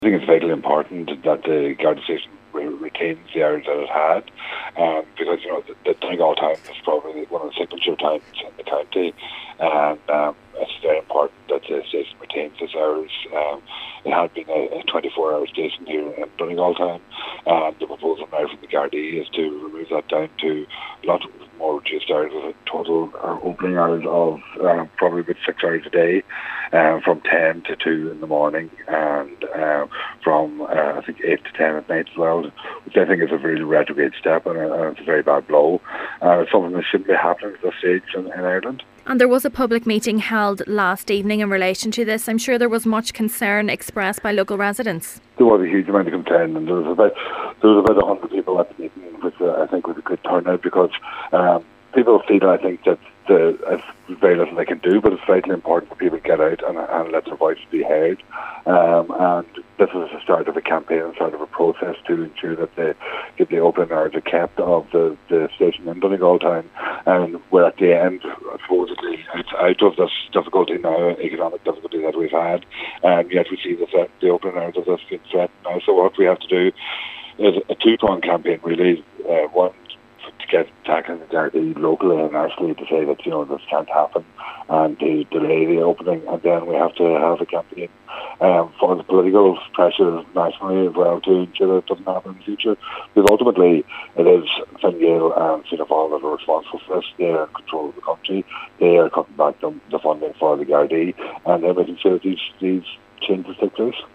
Deputy Pringle says this is now the start of a campaign to retain the opening hours of the station: